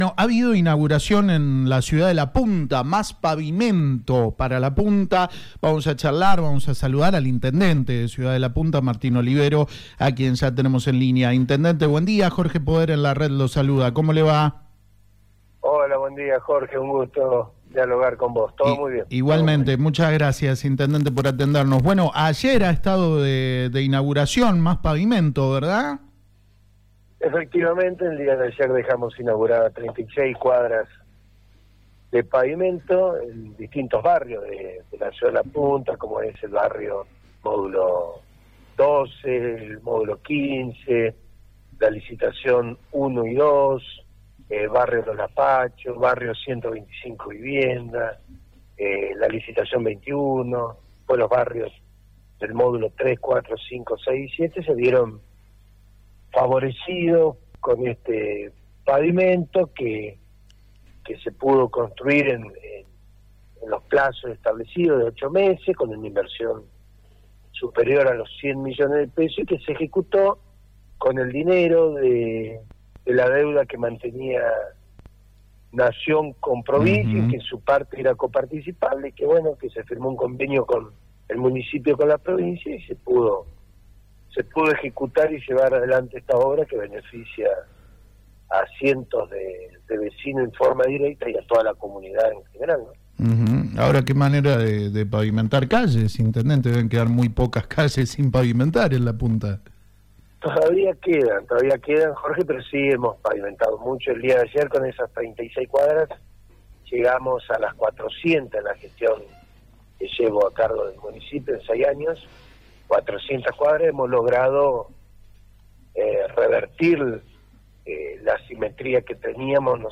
“En el día de ayer dejamos inaugurado 36 cuadras de pavimento, estamos contentos porque se pudo hacer dentro de los plazos establecidos. Es una gran obra para todos los vecinos”, concluyó el intendente de La Punta, Martín Olivero, en diálogo con La Red San Luis.